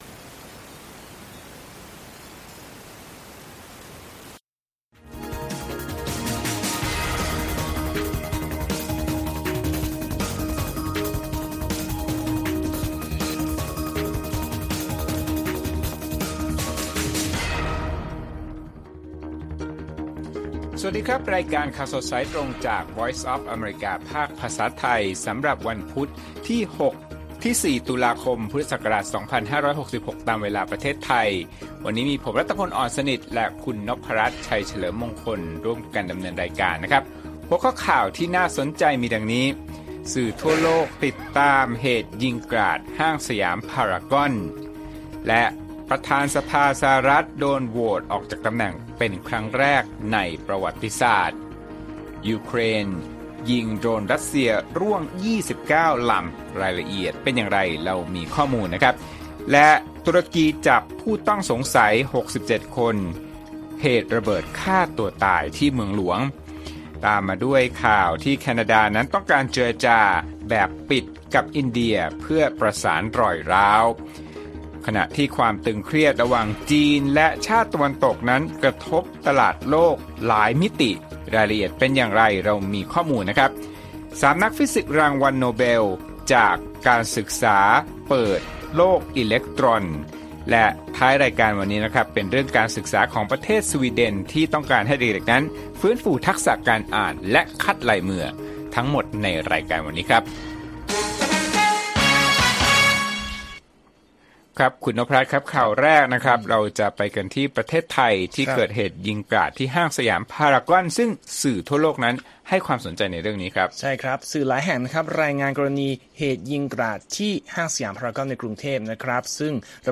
ข่าวสดสายตรงจากวีโอเอ ไทย พุธ ที่ 4 ตุลาคม 2566